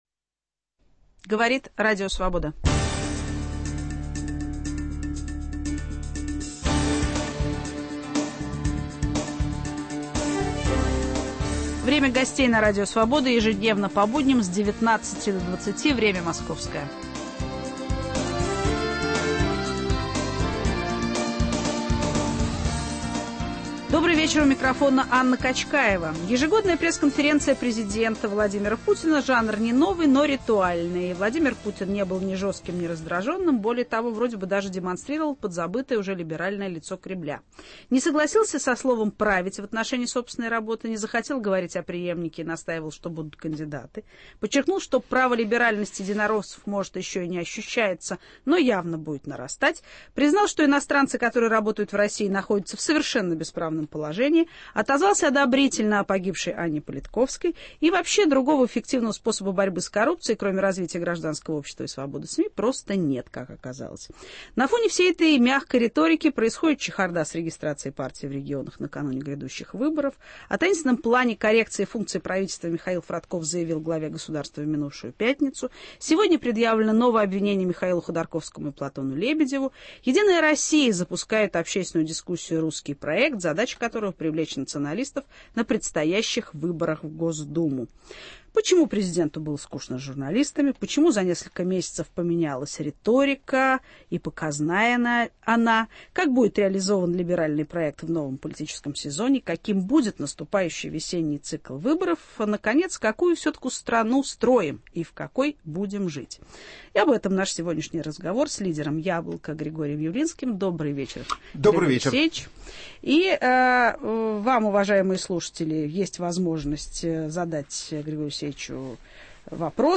Об этом наш сегодняшний разговор с лидером «Яблока» Григорием Явлинским.